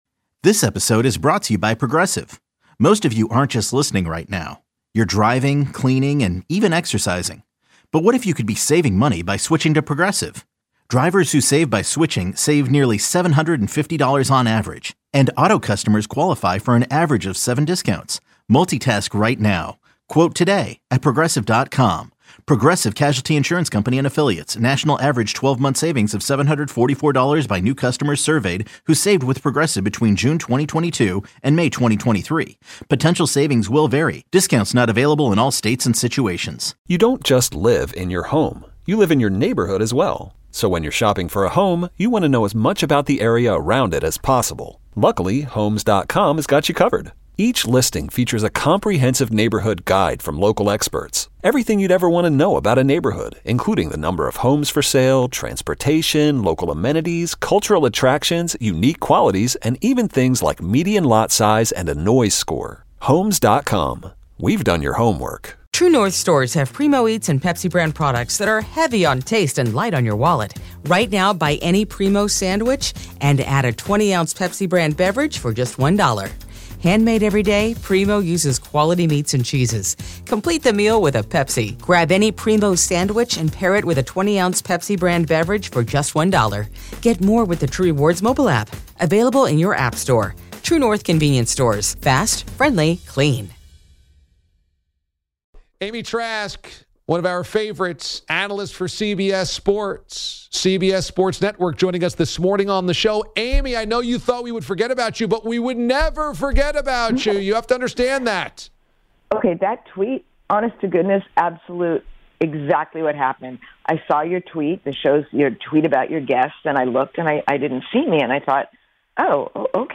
The DA Show Interviews